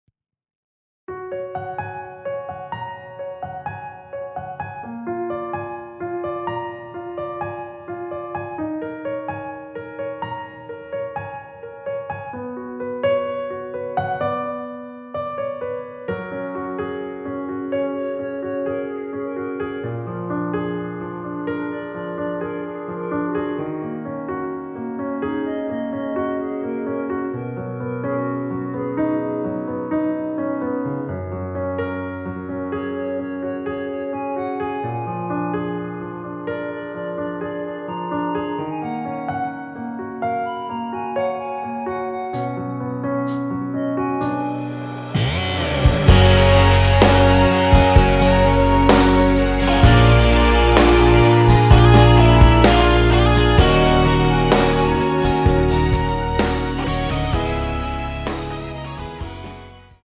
원키에서(+4)올린 (1절앞+후렴)으로 진행되게 편곡한 멜로디 포함된 MR입니다.
F#
앞부분30초, 뒷부분30초씩 편집해서 올려 드리고 있습니다.
중간에 음이 끈어지고 다시 나오는 이유는